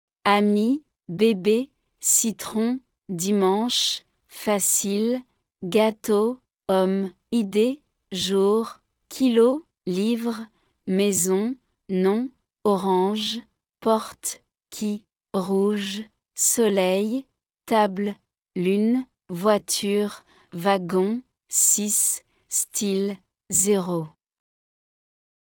Օրինակ բառերի արտասանություն